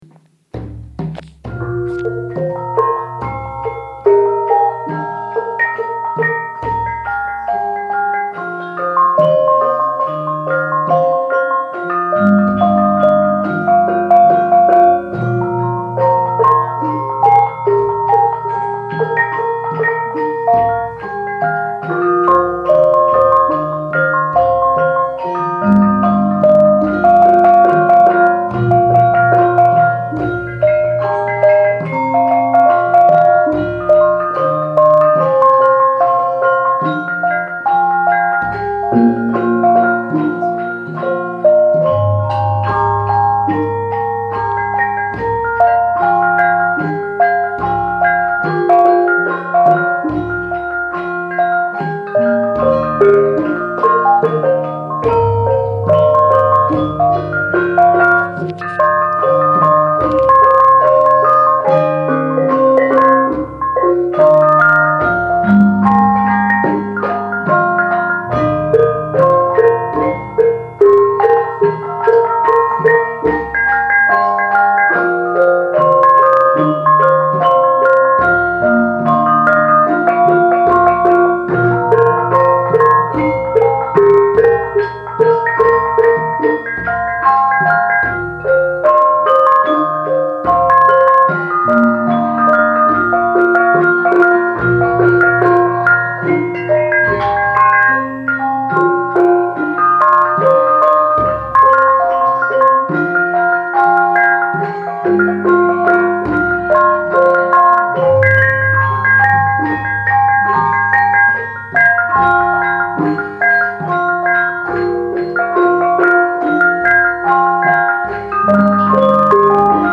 Description: Sound recording of 'piece for Gamelan and Viol Consort' (fragment 2) (performed by Gamelan Sekar Petak)